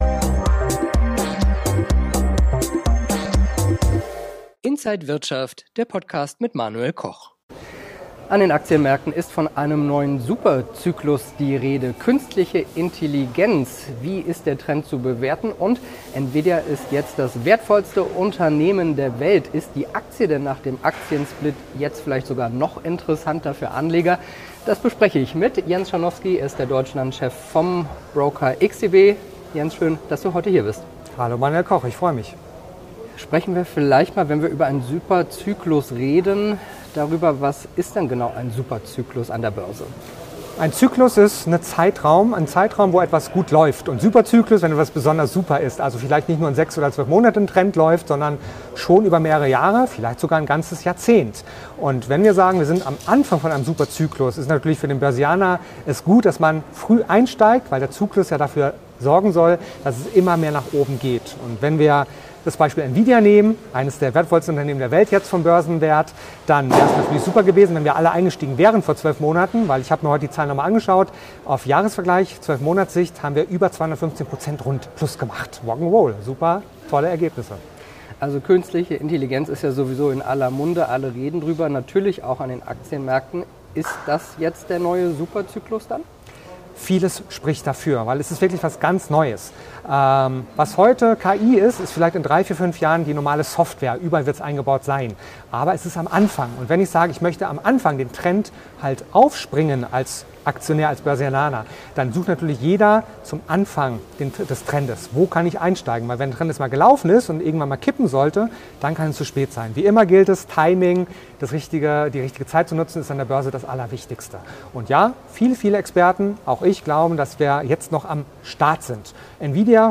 Alle Details im Interview mit Inside